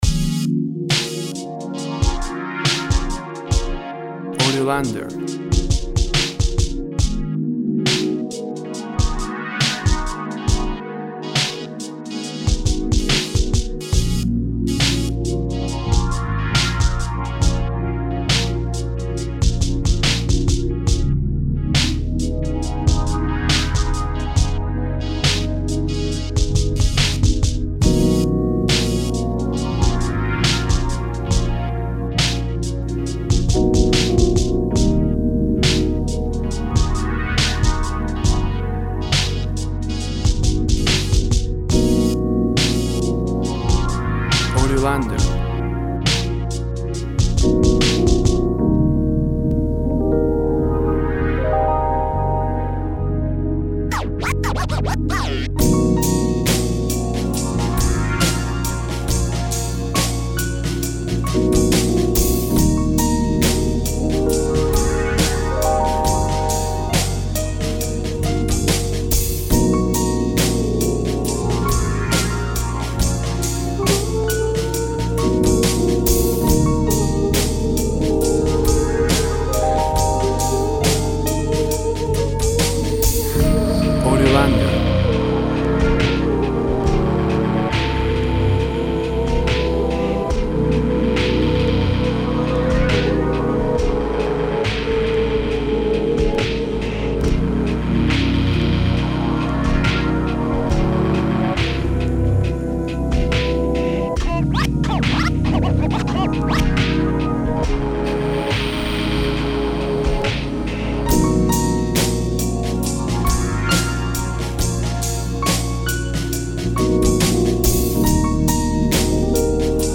Tempo (BPM) 95